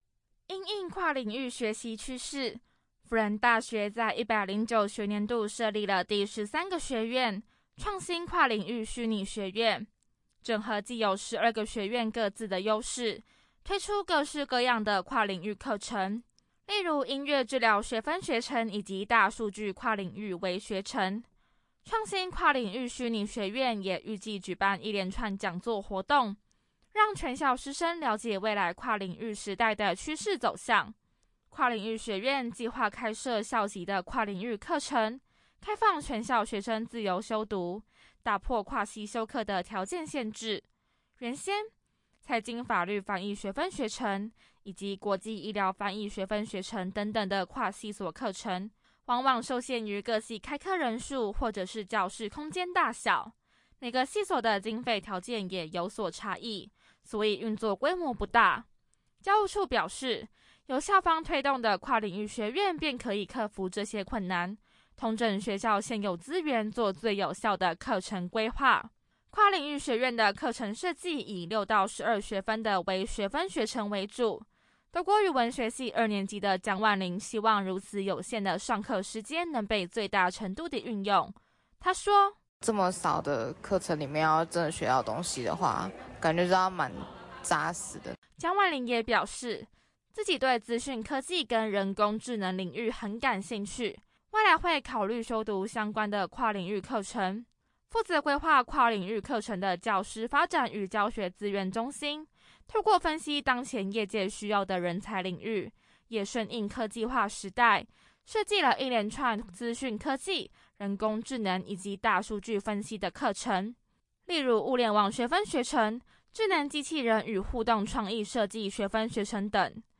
輔大之聲記者
採訪報導